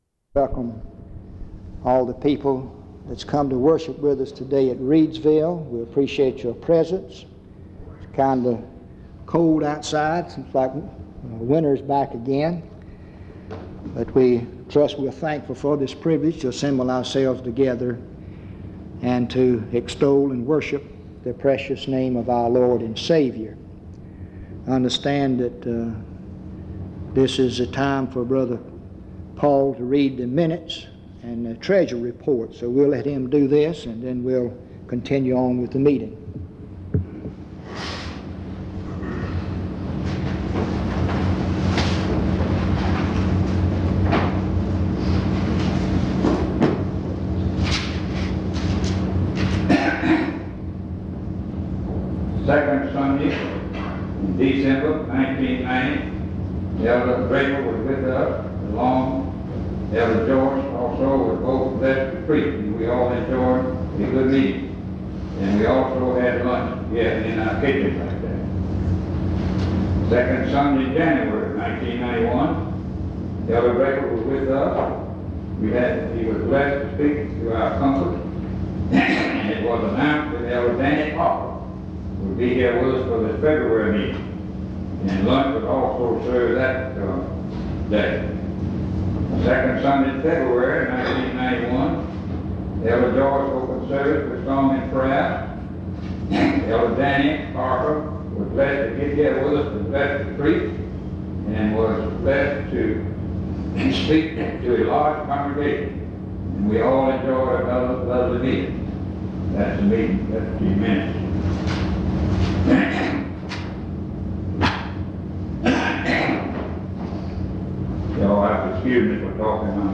En Collection: Reidsville/Lindsey Street Primitive Baptist Church audio recordings Miniatura Título Fecha de subida Visibilidad Acciones PBHLA-ACC.001_001-A-01.wav 2026-02-12 Descargar PBHLA-ACC.001_001-B-01.wav 2026-02-12 Descargar